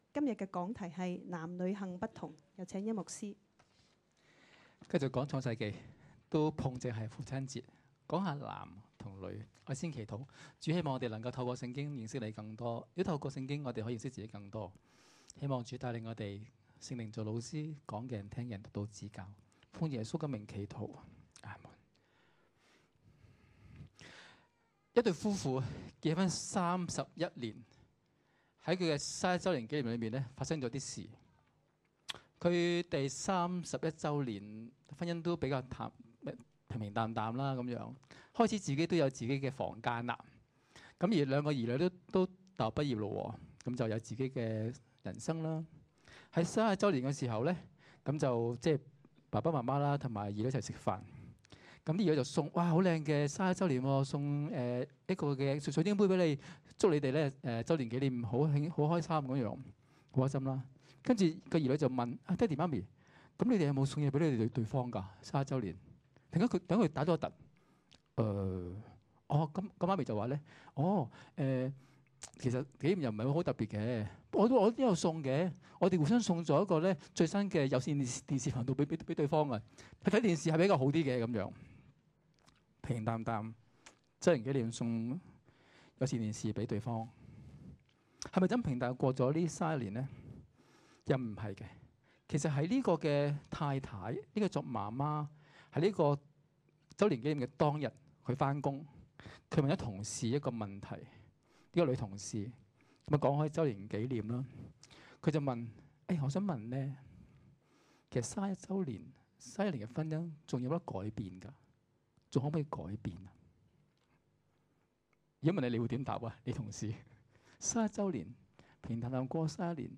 2016年6月18日及19日崇拜
2016年6月18日及19日講道